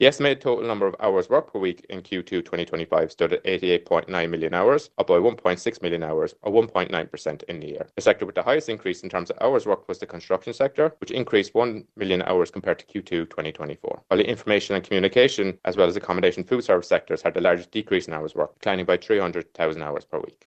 The construction sector is one of the largest employers in the region, also. Statistician